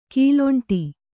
♪ kīloṇṭi